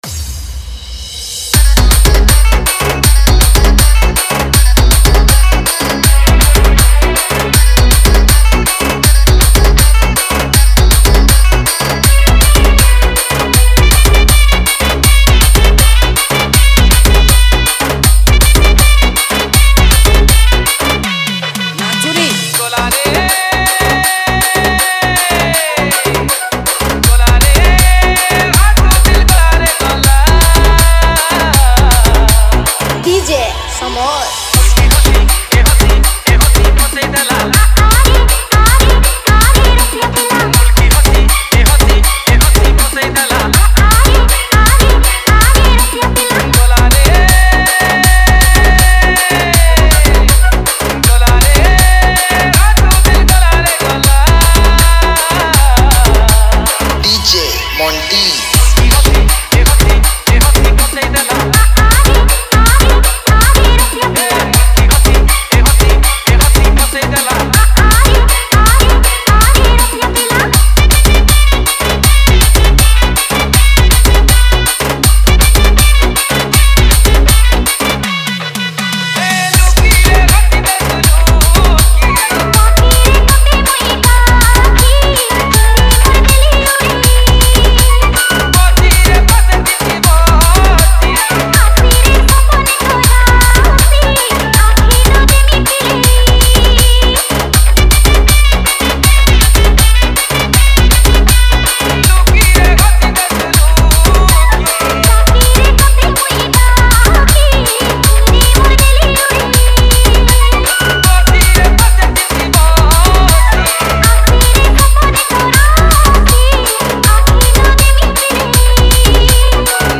Category:  New Sambalpuri Dj Song 2020